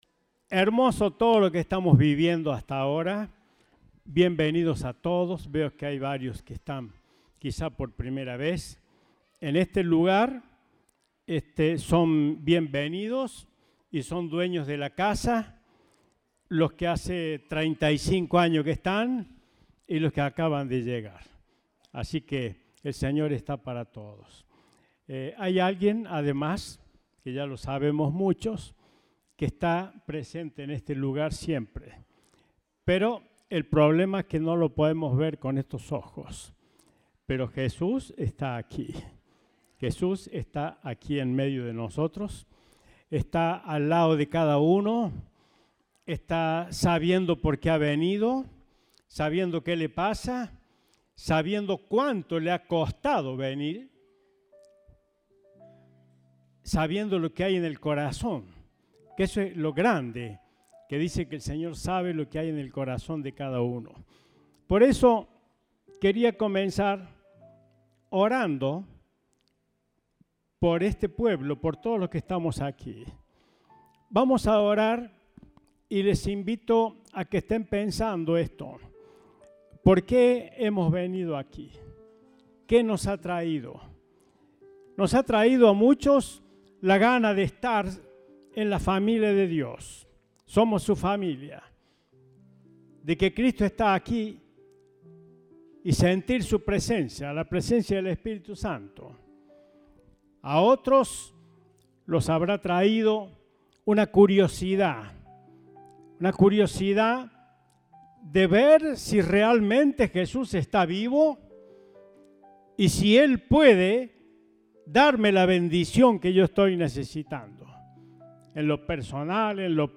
Compartimos el mensaje del Domingo 24 de Abril de 2022.
El himno que disfrutamos durante el mensaje es el siguiente: